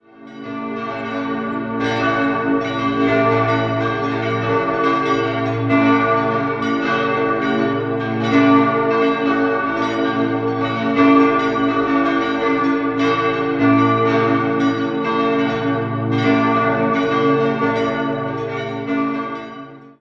Die große Glocke wurde 1968 von Rudolf Perner in Passau gegossen, Nr. 2 und 3 im Jahr 1649 von Franciscus Guiot in München, Glocke 4 im Jahr 1770 von den Gebrüdern Bimbi aus Villa Collemandina und die beiden kleinsten 1761/62 von Johann Florido in Straubing.